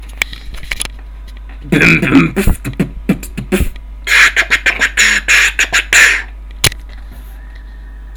Форум российского битбокс портала » Реорганизация форума - РЕСТАВРАЦИЯ » Выкладываем видео / аудио с битбоксом » бит с abra scratch
бит с abra scratch
Bm Bm pf t b b tt pf [abra]ту тука тука ту ту тука ту[abra]